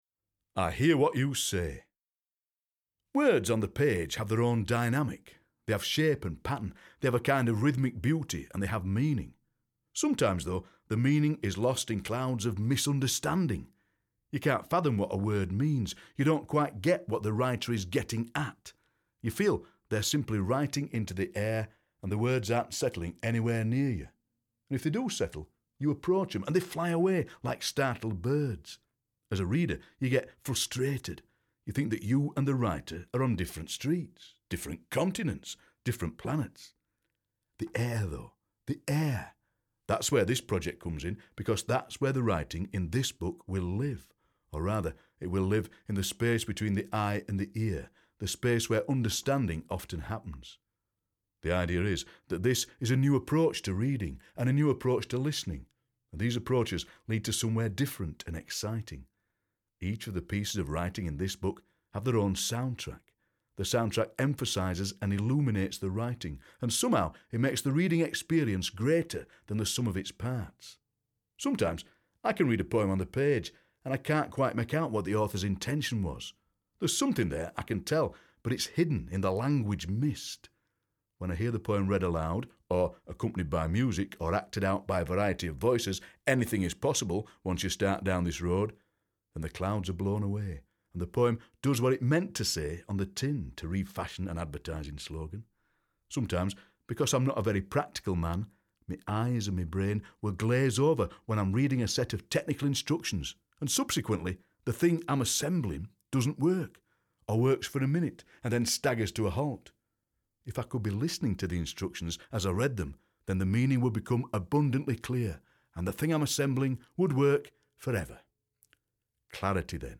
Ian is a Yorkshireman; he writes the way he speaks, and he speaks with a voice like a pint of strong dark beer in a big dimpled glass.